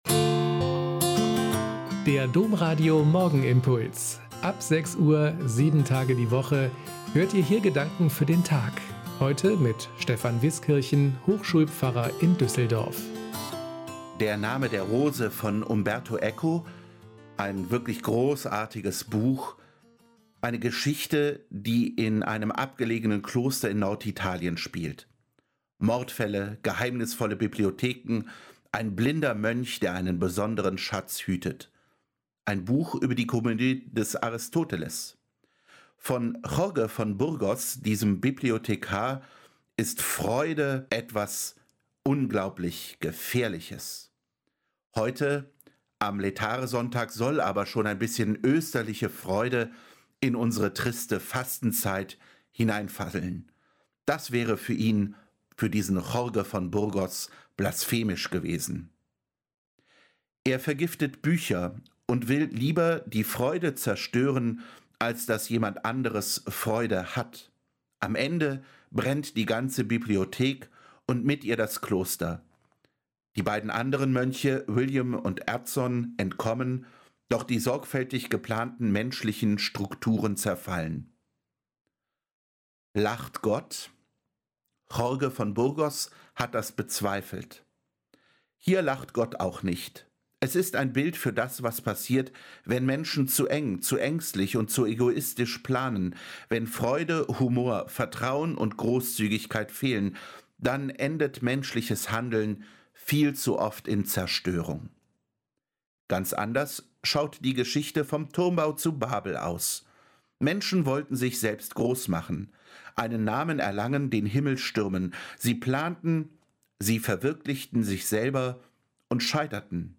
Morgenimpuls